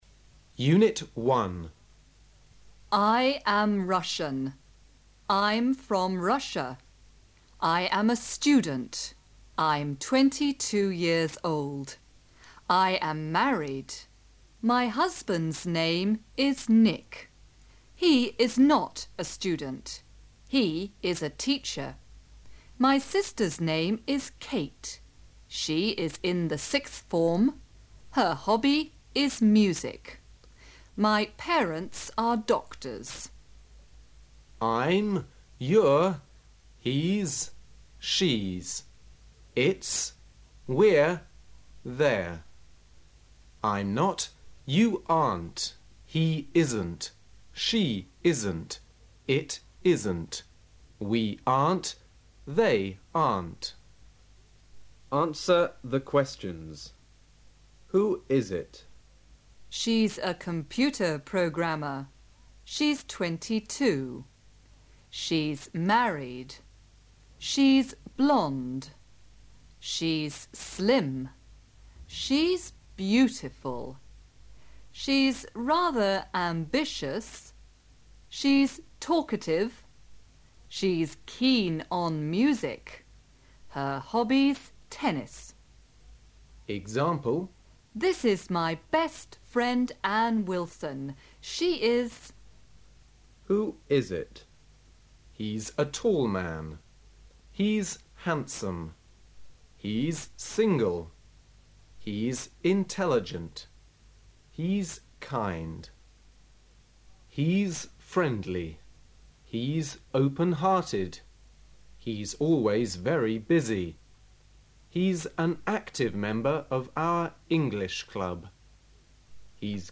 Студийная Кассета из СССР. Шло как приложение к учебнику.